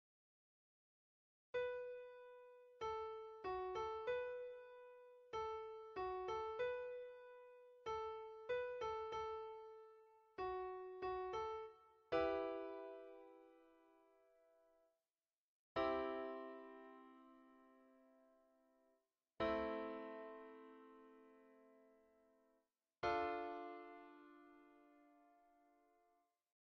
Piano Synthesia Tutorial